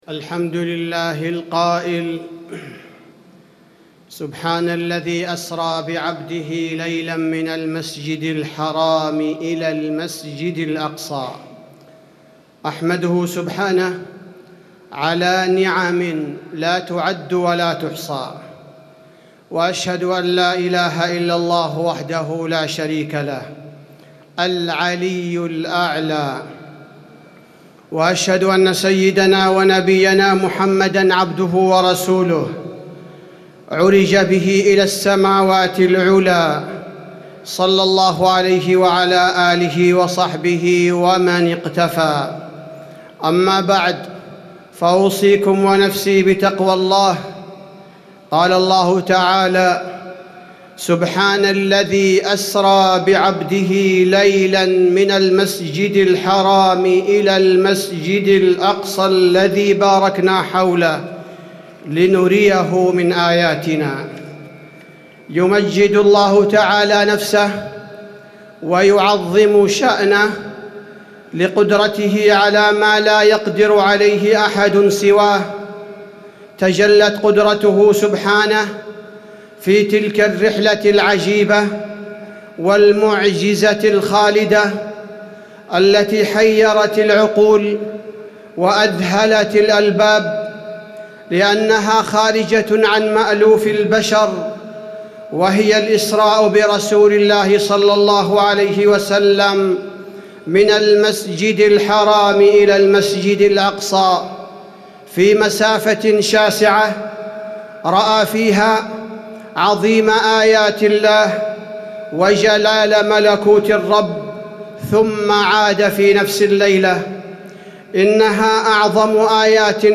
تاريخ النشر ١١ ربيع الثاني ١٤٣٩ هـ المكان: المسجد النبوي الشيخ: فضيلة الشيخ عبدالباري الثبيتي فضيلة الشيخ عبدالباري الثبيتي رحلة الإسراء والمعراج دروس وعبر